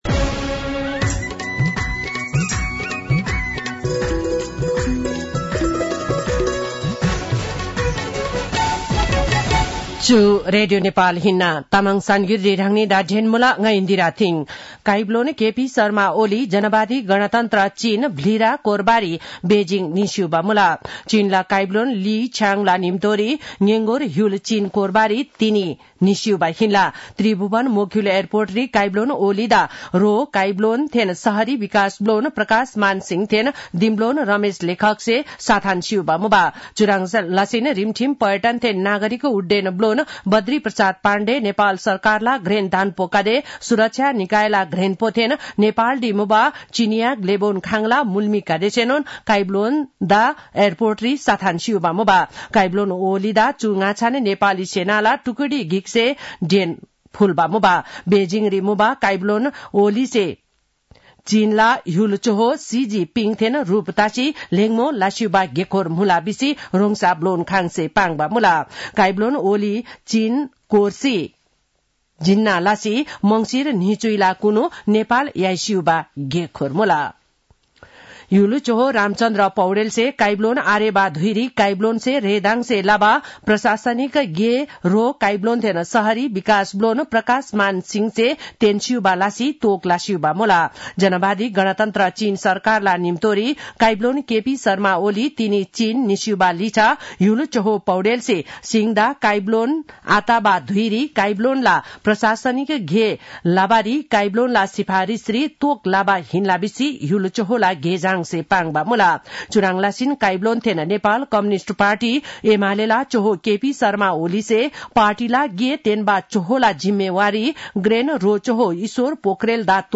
तामाङ भाषाको समाचार : १८ मंसिर , २०८१